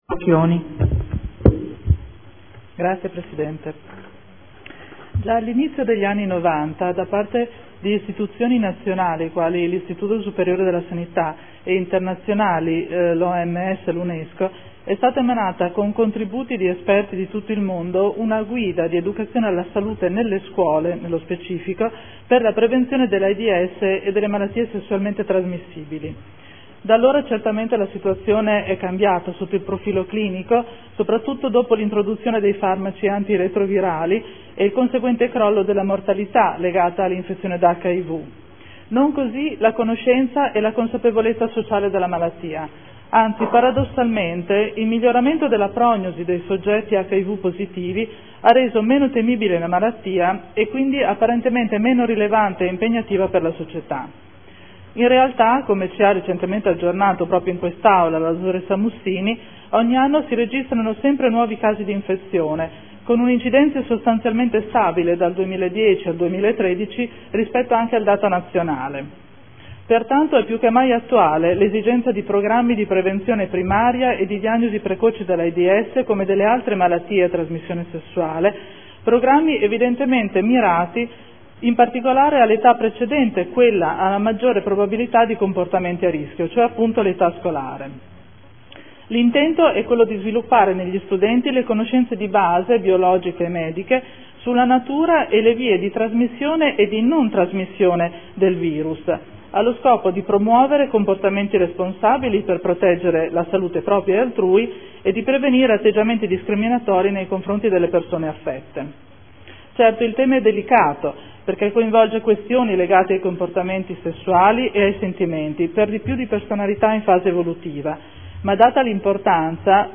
Seduta del 05/02/2015 Dibattito.